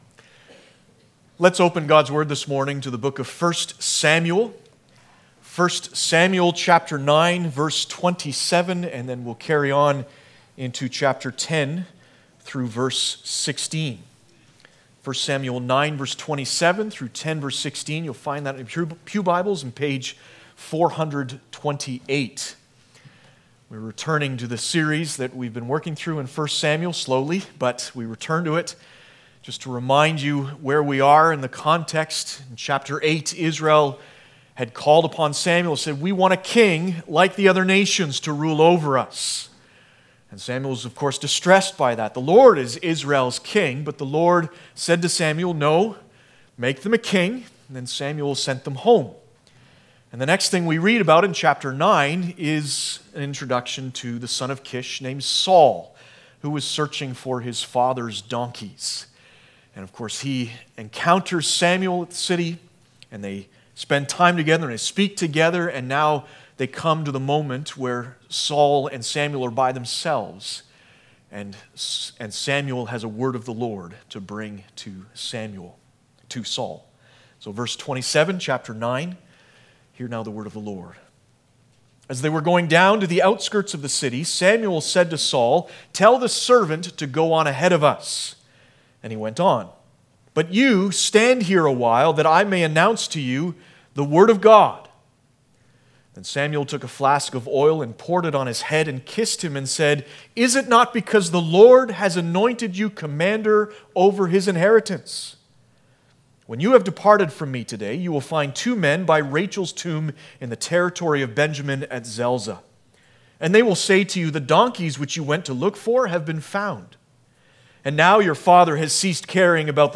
1 Samuel Passage: 1 Sam 9:27-10:16 Service Type: Sunday Morning « Mercy and truth have met together!